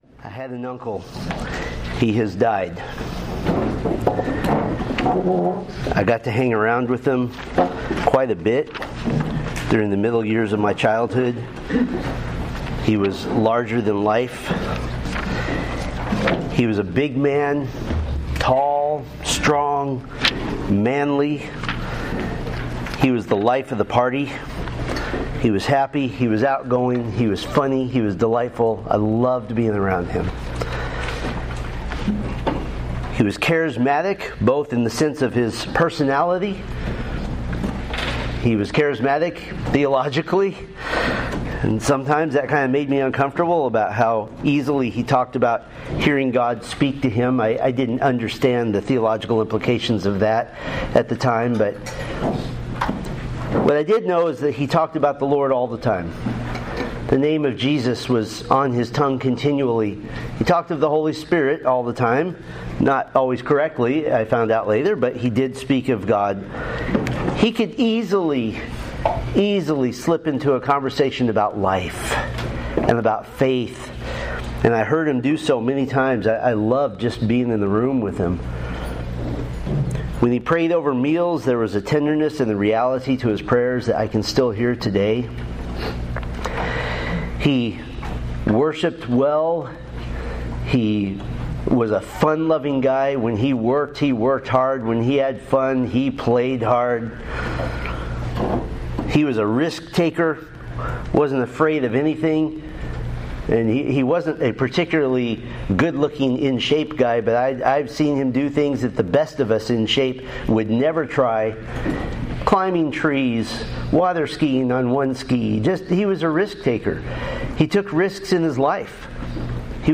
Date: Apr 5, 2025 Series: First Watch Men's Breakfasts Grouping: Men's Ministry Events More: Download MP3